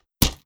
Close Combat Attack Sound 2.wav